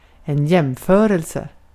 Ääntäminen
US GenAm: IPA : /kəmˈpɛɹɪsən/ IPA : /kəmˈpæɹɪsən/ RP : IPA : /kəmˈpæɹɪsən/